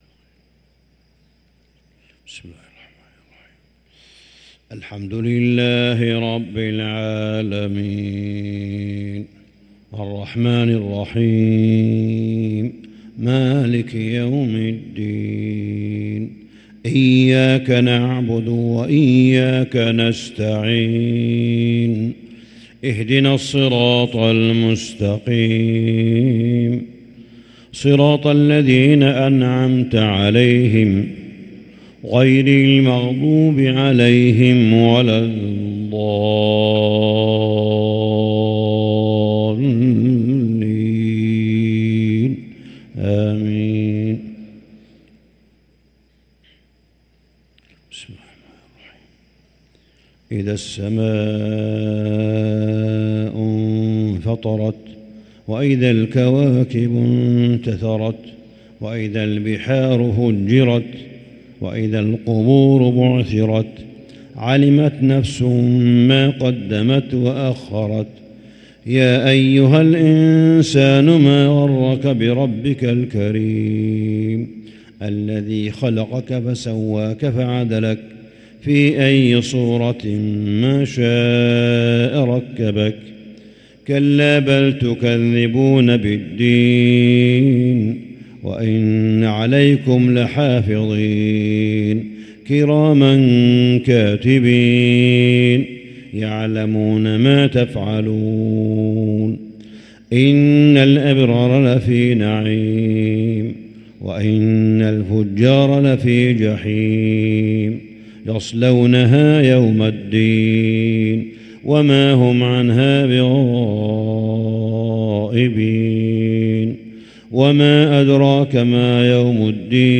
صلاة الفجر للقارئ صالح بن حميد 8 رمضان 1444 هـ
تِلَاوَات الْحَرَمَيْن .